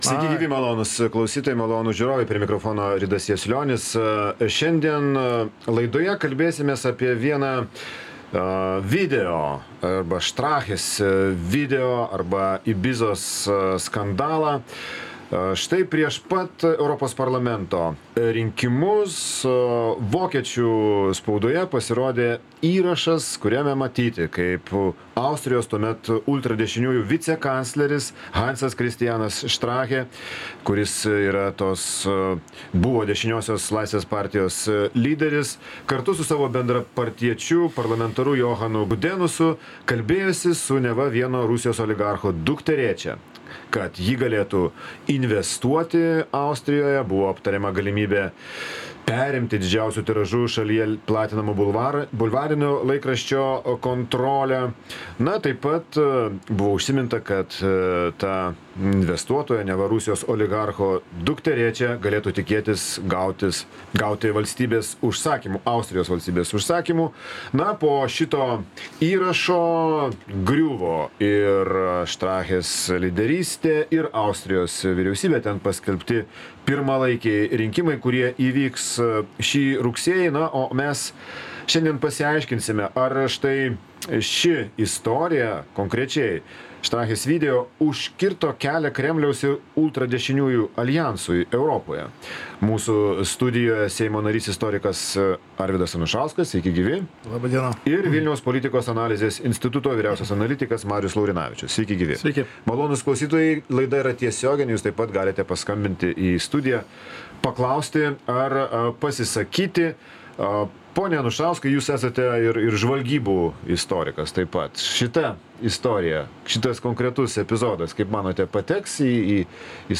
Laidoje dalyvauja Seimo narys, istorikas